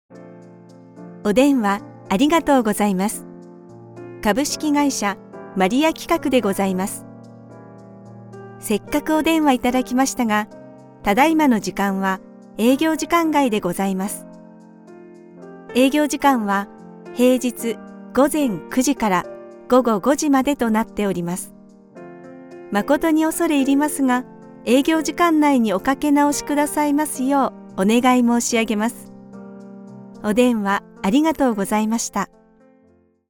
Kommerziell, Natürlich, Zuverlässig, Warm, Corporate
Telefonie
personable, persuasive, versatile, warm and authentic